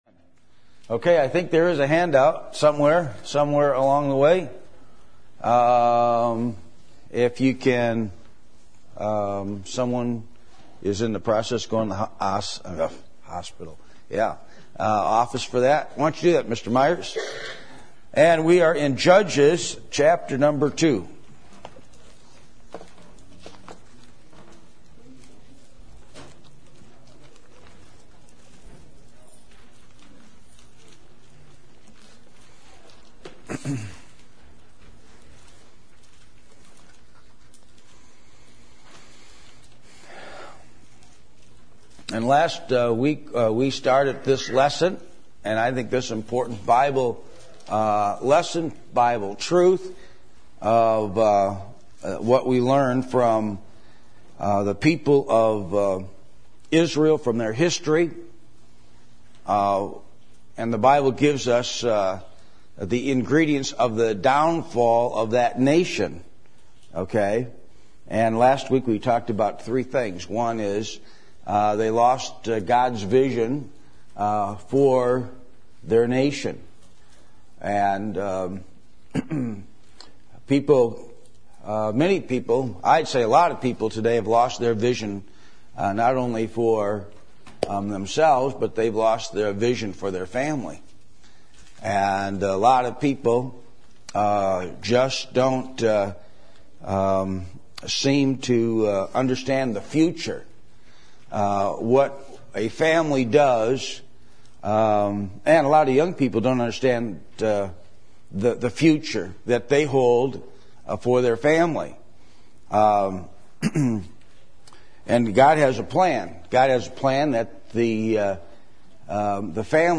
Adult Sunday School %todo_render% « The Profound Effect of the Tongue